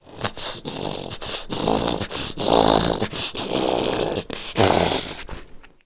DayZ-Epoch/SQF/dayz_sfx/zombie/idle_6.ogg at a11d2891c46dfb8aaba0d40acb0ede3a2b35ec2c